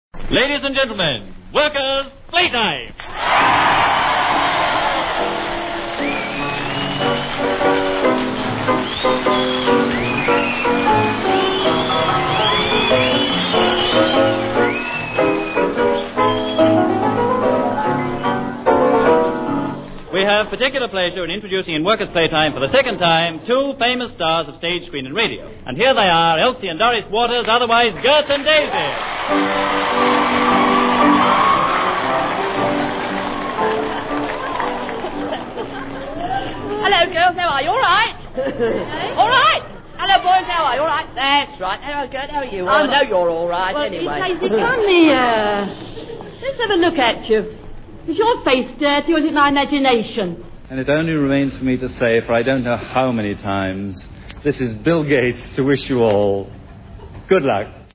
This was a comedy and music show which was to continue for 23 years, not least because the Government was anxious to sustain cheery, productive efforts in the workplace after the War, as the Country recovered.
The programme came live from a factory canteen or shop floor, chosen by the Ministry of Labour, "Somewhere in Britain". Broadcast three times a week, it featured a couple of pianos and a crew of touring variety artists.